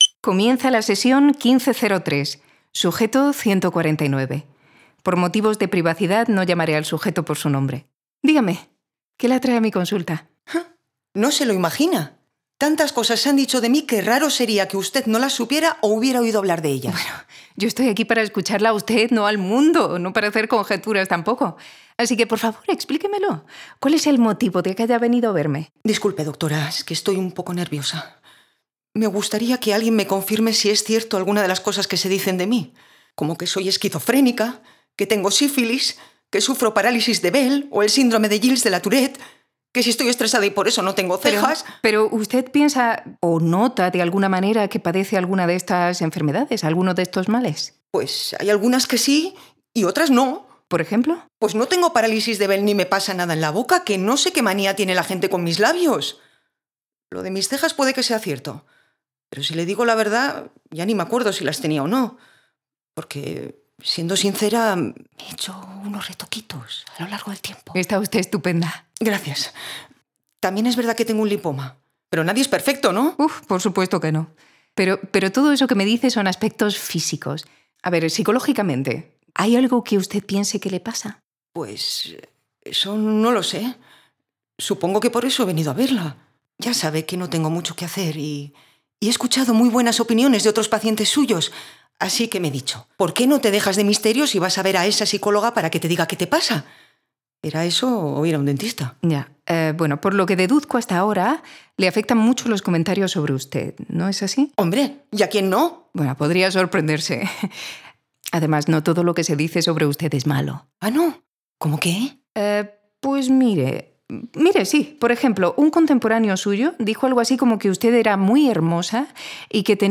Format: Audio Drama
Voices: Full cast
Soundscape: Voices only